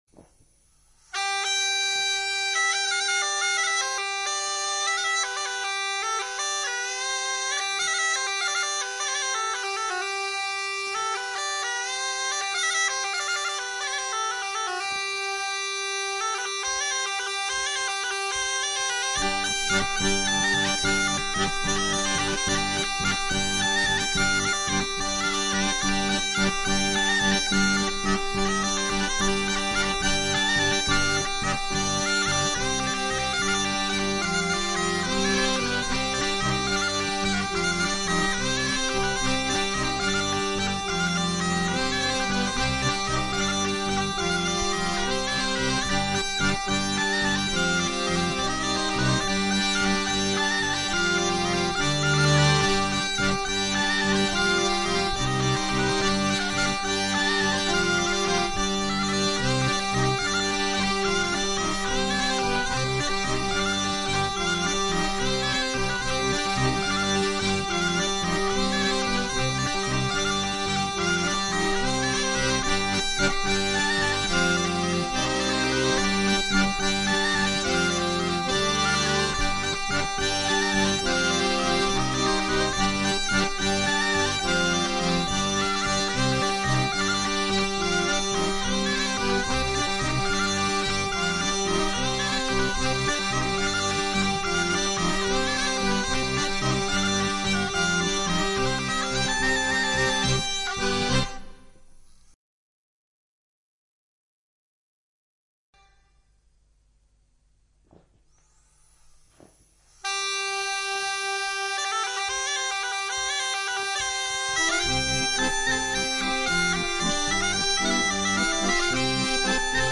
Registrazioni live: